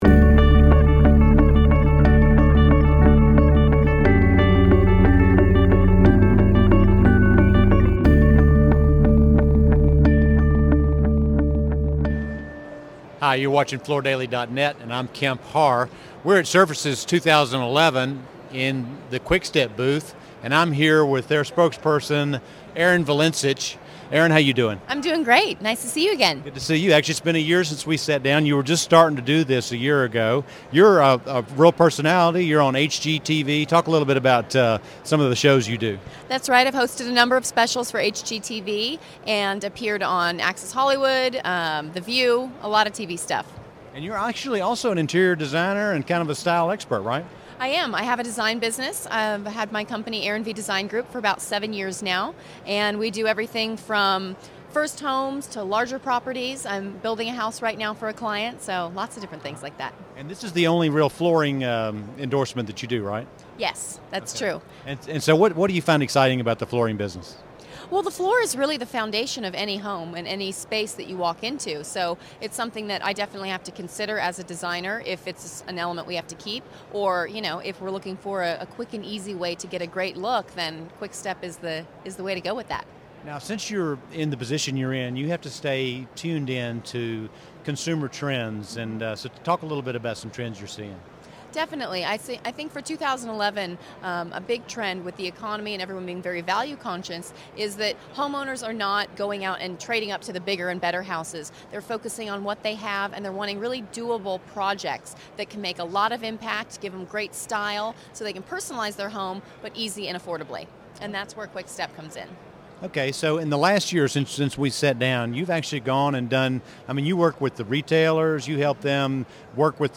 2011—Recorded at Surfaces